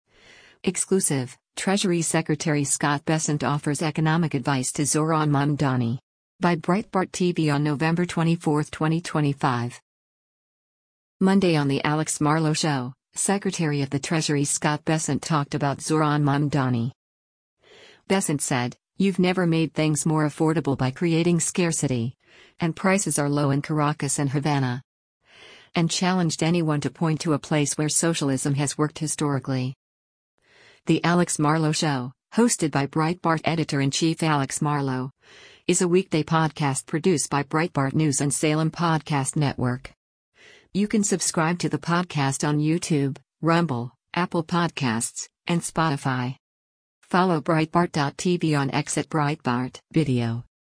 Monday on “The Alex Marlow Show,” Secretary of the Treasury Scott Bessent talked about Zohran Mamdani.